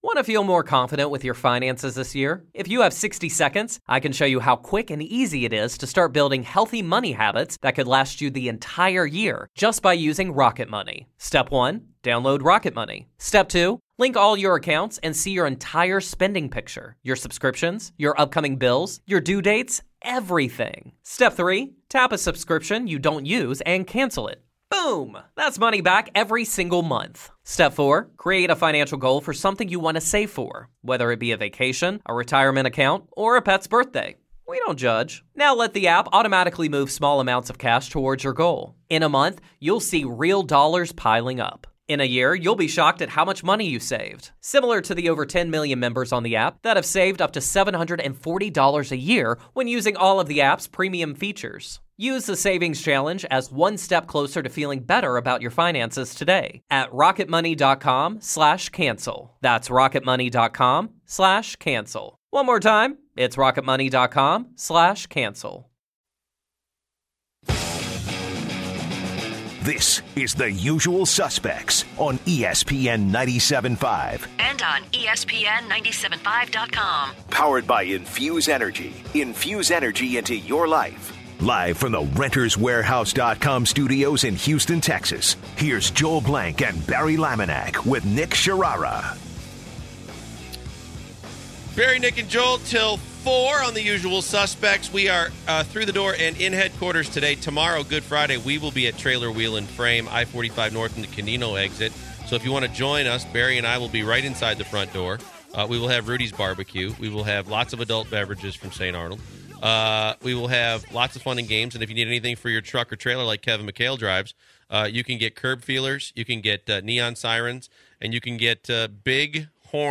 04/13/2017 Kevin McHale interview